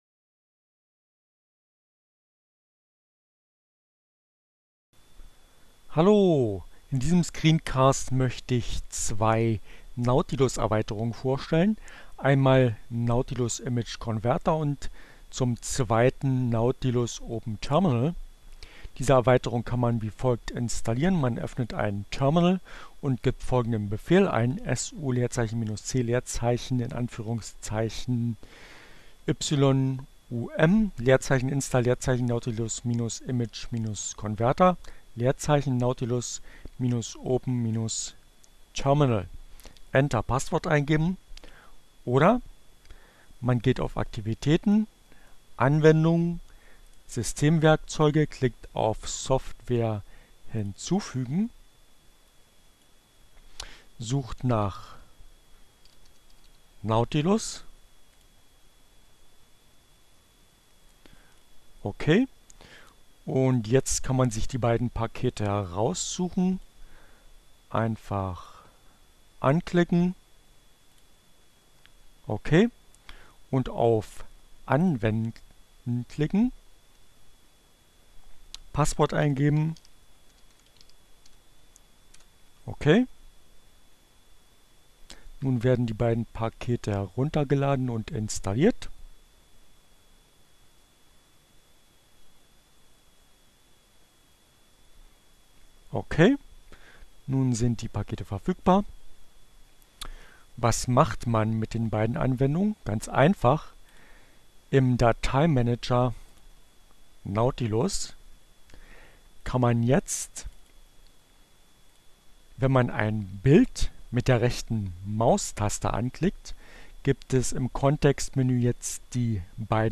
Tags: CC by-sa, Fedora, Gnome, Linux, nautilus, Neueinsteiger, Ogg Theora, ohne Musik, screencast, gnome3, Tutorial